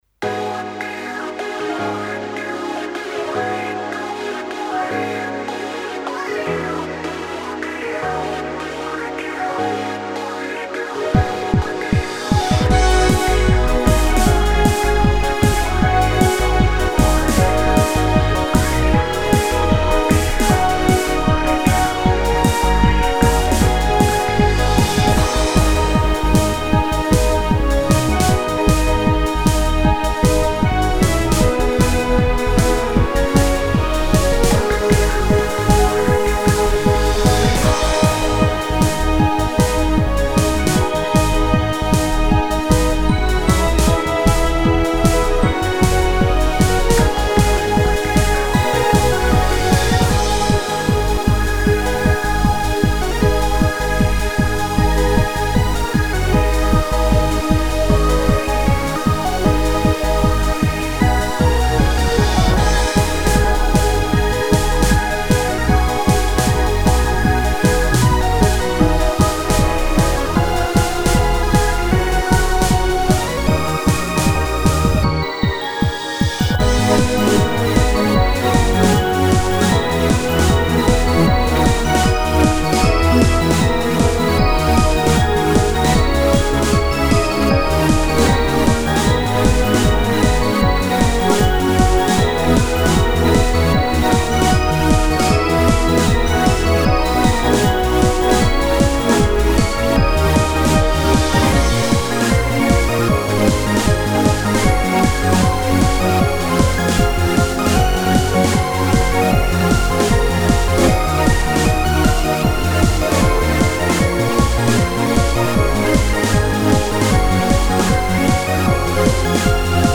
フリーBGM バトル・戦闘 4つ打ちサウンド
フェードアウト版のmp3を、こちらのページにて無料で配布しています。